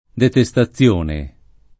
detestazione [ dete S ta ZZL1 ne ] s. f.